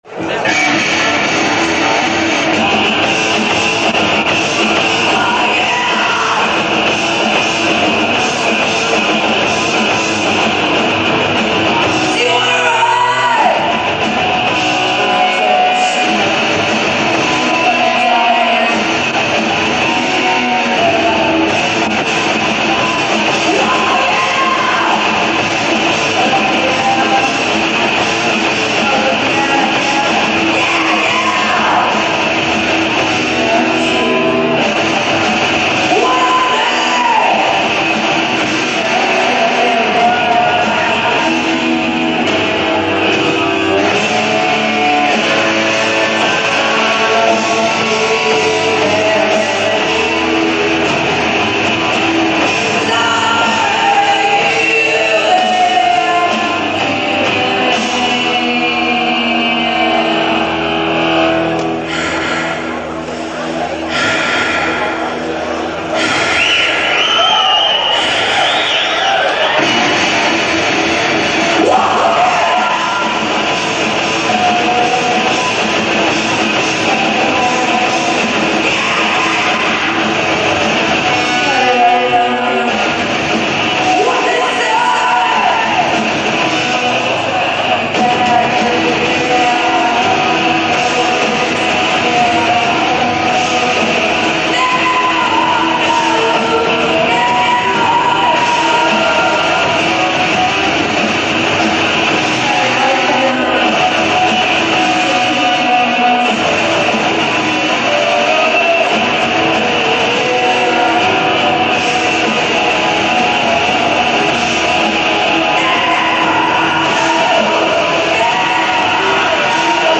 live 1990-1992
Vooruit, Ghent, Belgium